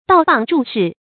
道傍筑室 dào bàng zhù shì
道傍筑室发音
成语注音ㄉㄠˋ ㄅㄤˋ ㄓㄨˋ ㄕㄧˋ